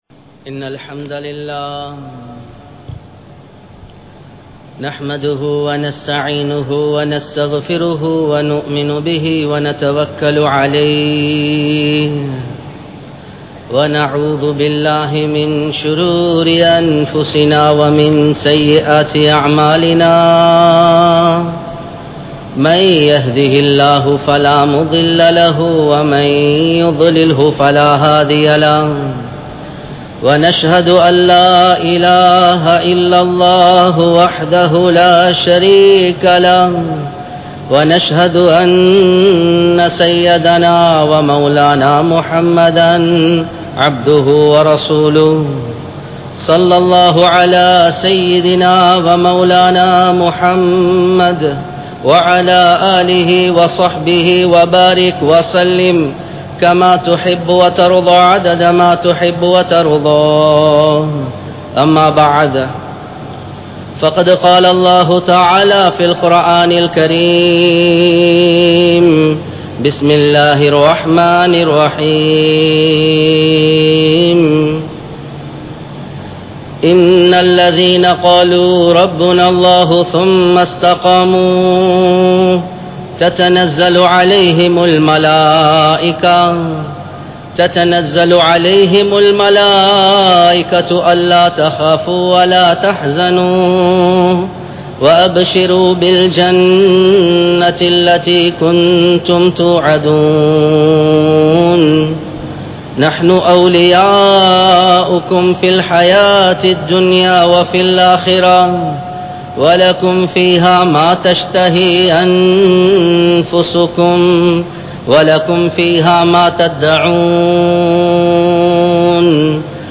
Saha Vaalvu(Coexistence) | Audio Bayans | All Ceylon Muslim Youth Community | Addalaichenai